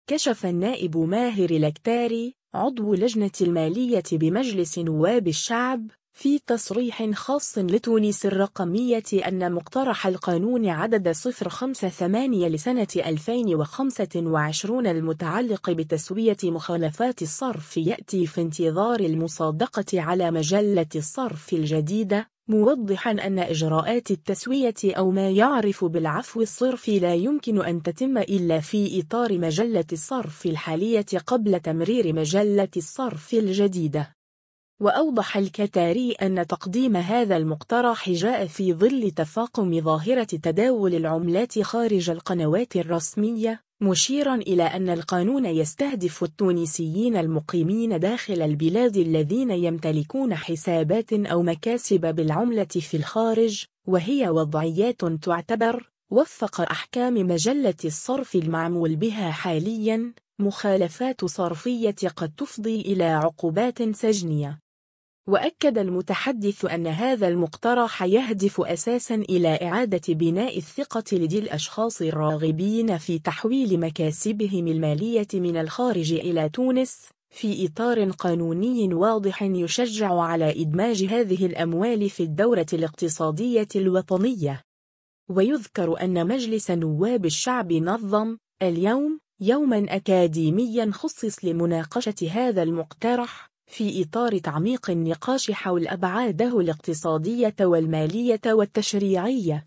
كشف النائب ماهر الكتاري، عضو لجنة المالية بمجلس نواب الشعب، في تصريح خاص لـ”تونس الرقمية” أن مقترح القانون عدد 058 لسنة 2025 المتعلق بتسوية مخالفات الصرف يأتي في انتظار المصادقة على مجلة الصرف الجديدة، موضحًا أن إجراءات التسوية أو ما يُعرف بـ”العفو الصرفي” لا يمكن أن تتم إلا في إطار مجلة الصرف الحالية قبل تمرير مجلة الصرف الجديدة.